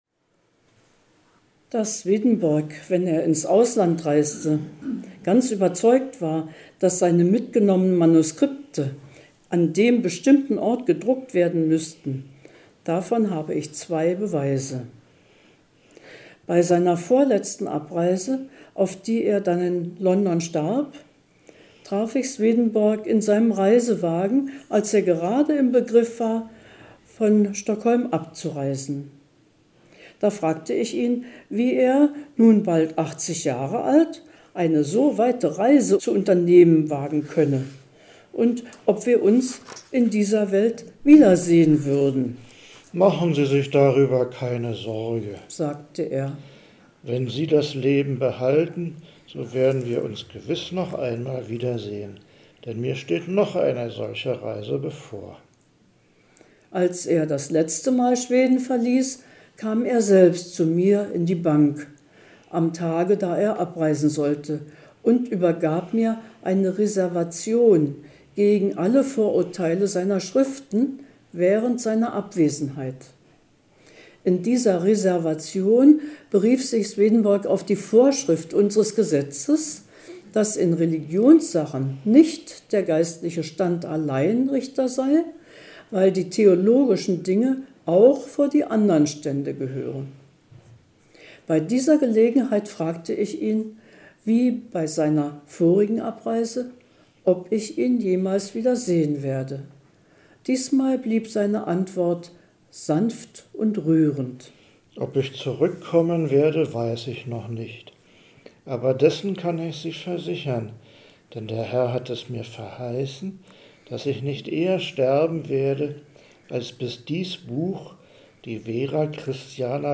Carl Robsahms Memoiren über Emanuel Swedenborg Lesung